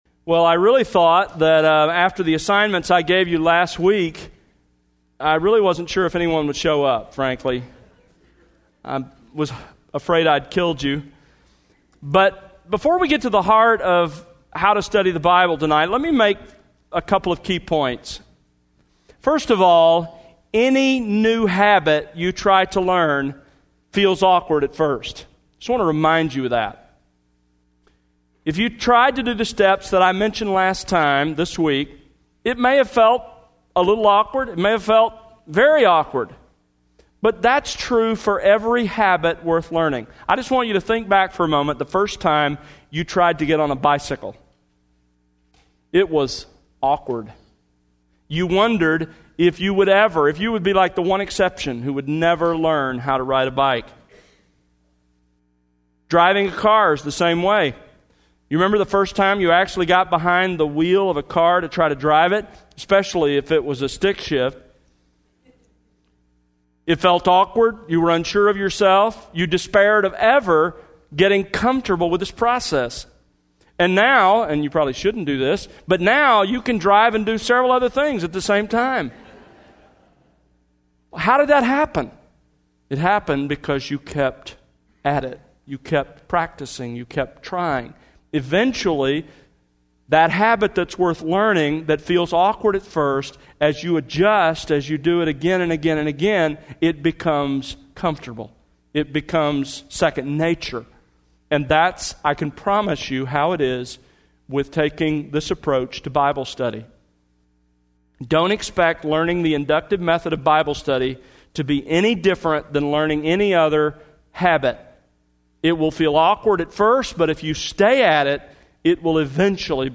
Sermons That Exalt Christ